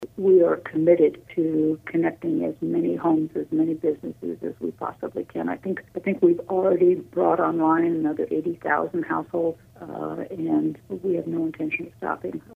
Governor Laura Kelly spoke with News Radio KMAN this week during an exclusive interview heard Wednesday on KMAN’s In Focus.